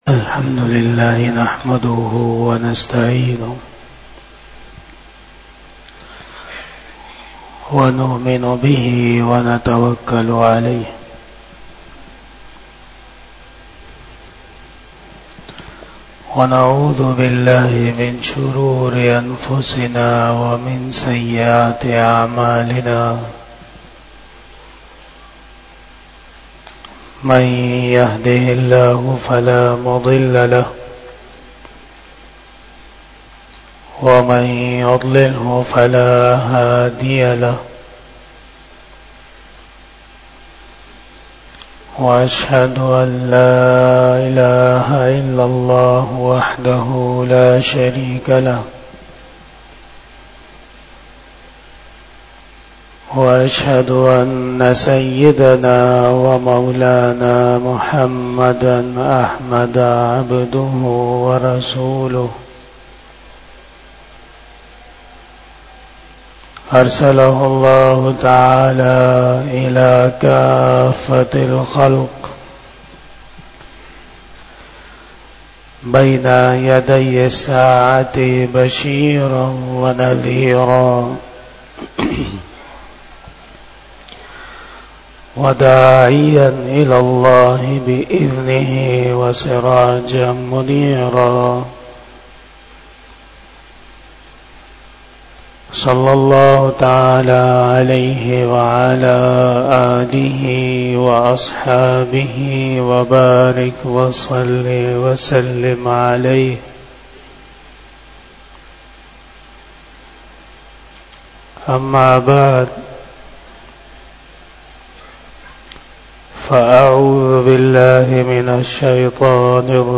51 Bayan E Jummah 22 December 2023 (08 Jamadi Us Sani 1445 HJ)